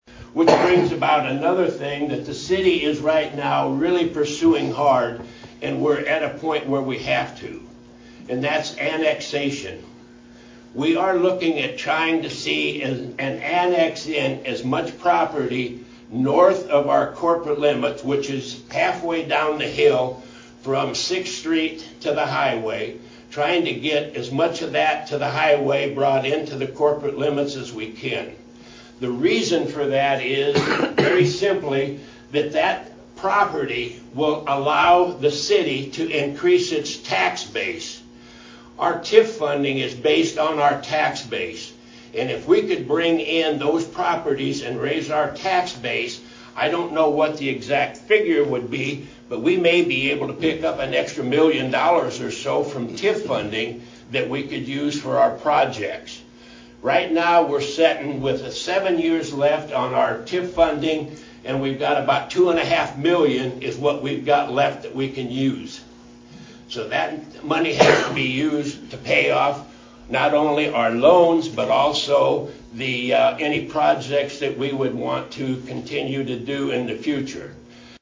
Mayor Phil Przychodizin, speaking at the recent Massena Annual Chamber of Commerce banquet, announced that the city is partnering with Greenfield Municipal Utilities to enhance the water treatment process.